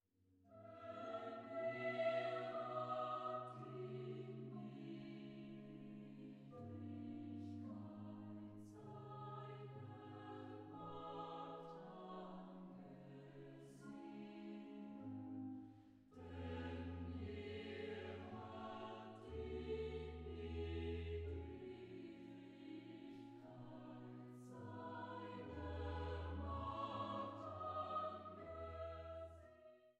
Schramm-Orgel der Stadtkirche St. Otto zu Wechselburg
in neun Versen für vierstimmigen Chor und Orgel